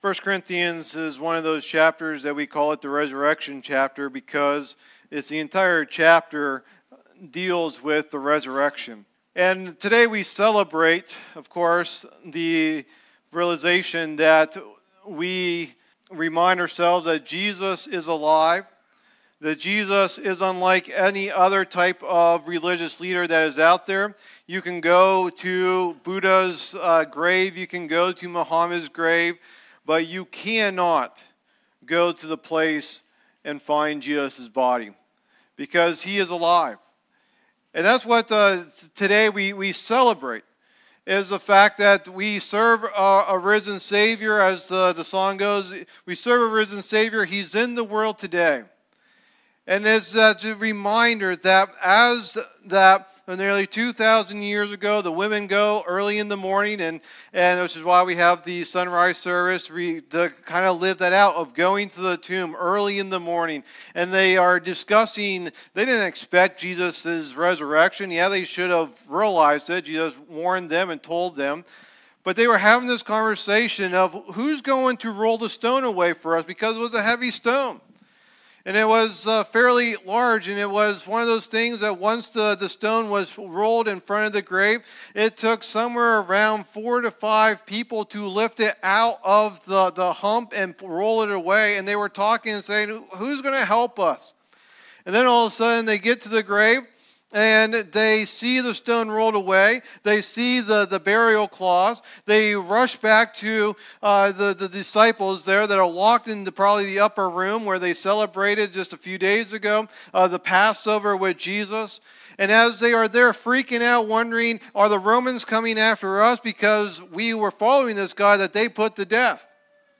Easter Message 2025